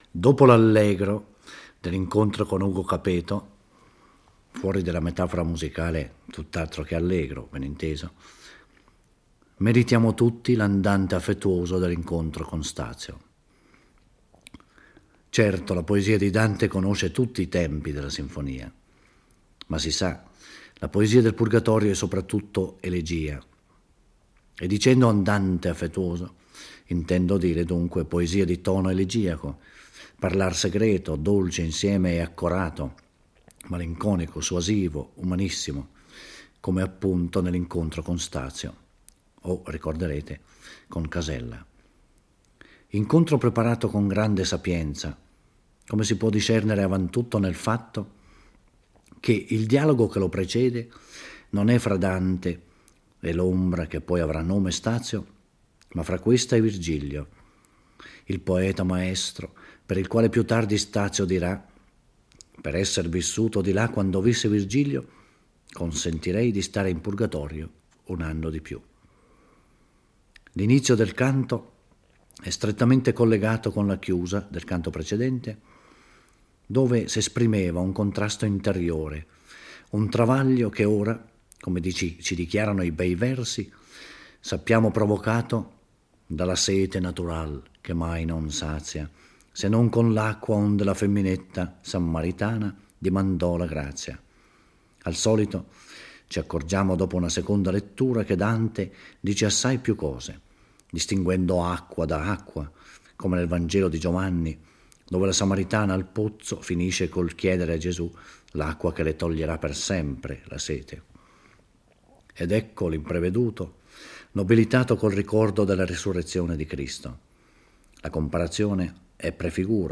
Giorgio Orelli legge e commenta il XXI canto del Purgatorio. Dante si interroga sul terremoto e sui gridi seguenti a cui ha appena assistito, ricevendo come risposta che si tratta di fenomeni di ordine divino, e non naturale.